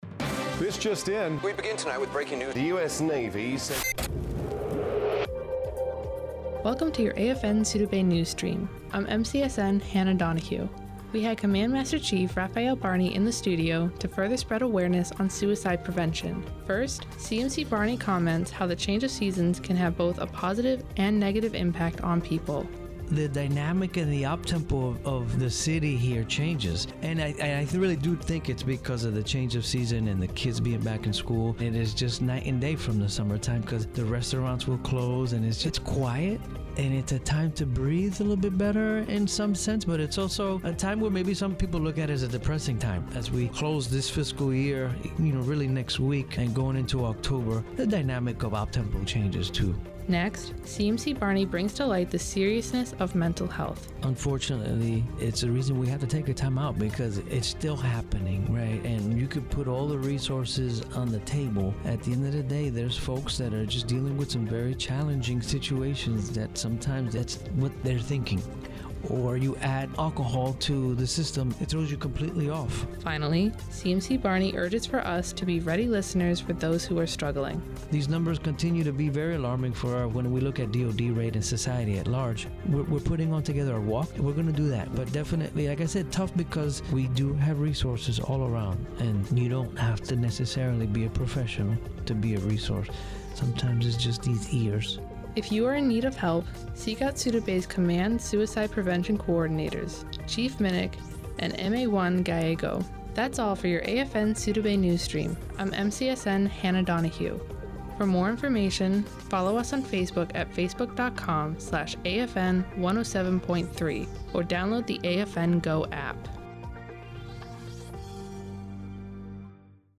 NewsAmerican Forces Network AFNSouda bay Greece